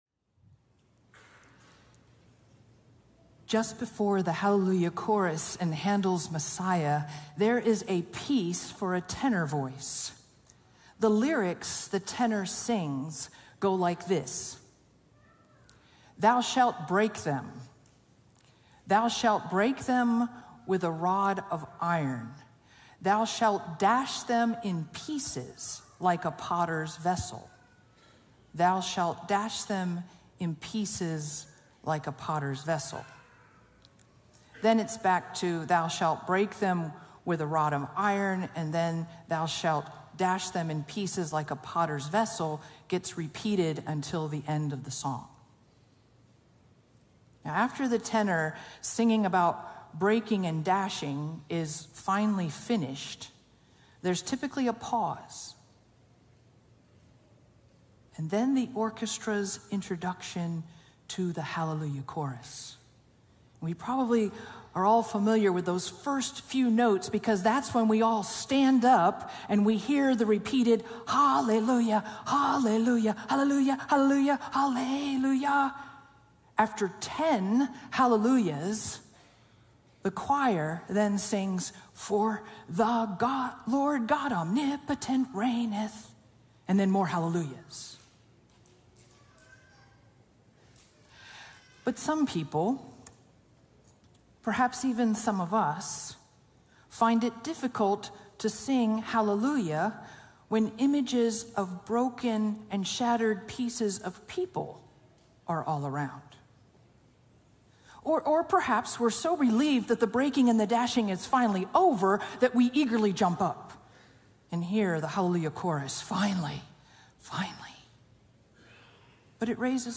Sermon Series Archive | La Sierra University Church